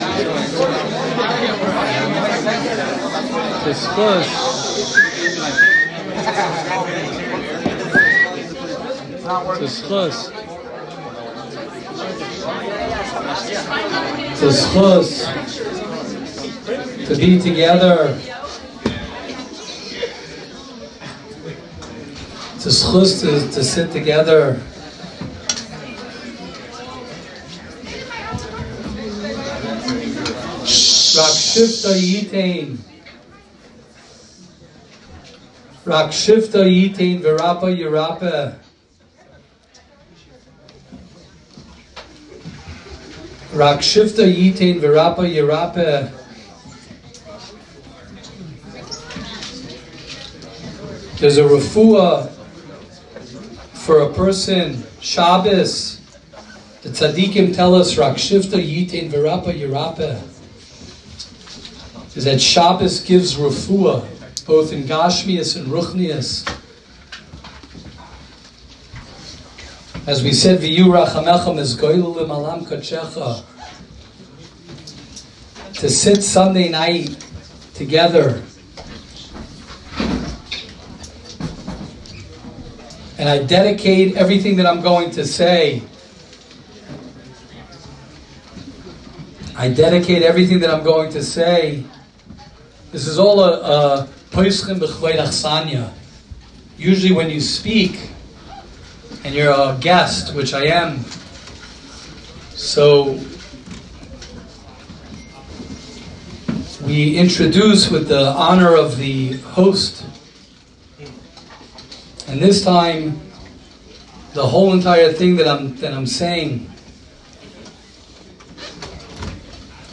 Shiur at Night of Inspiration in Monsey